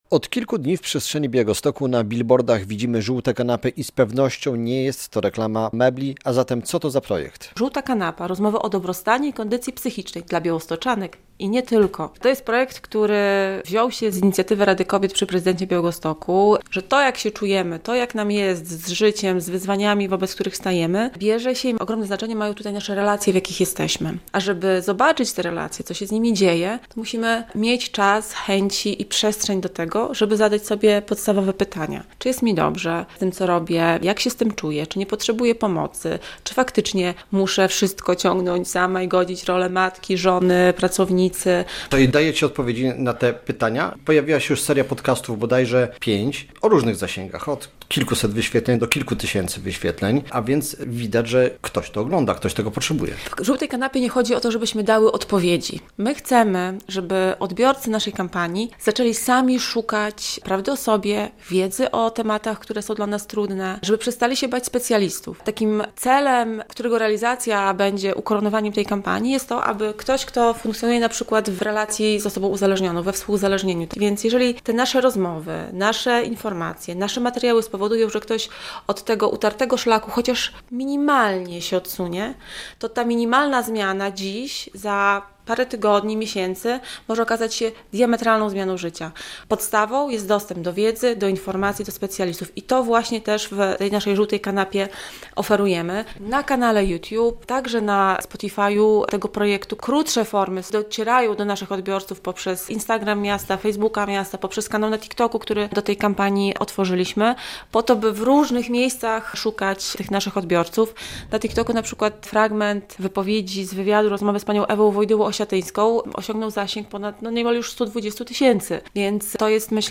W Białymstoku trwa kampania "Żółta Kanapa" na rzecz zdrowia psychicznego [rozmowa]